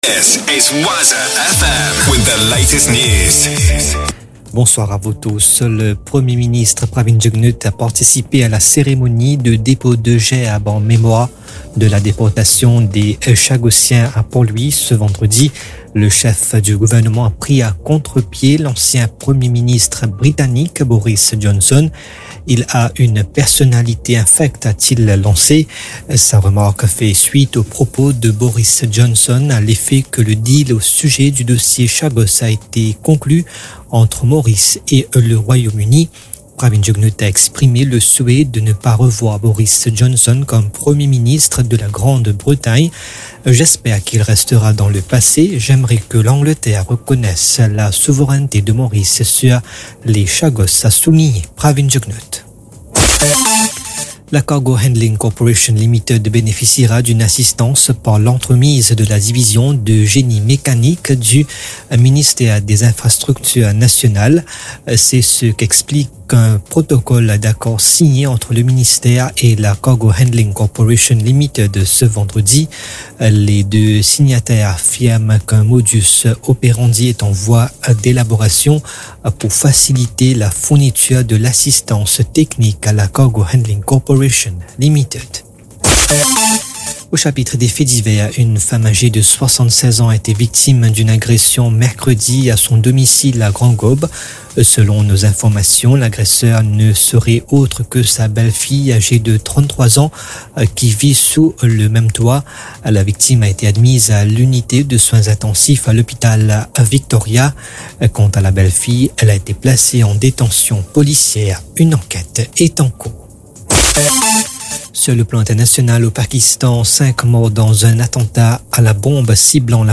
NEWS 20H - 3.11.23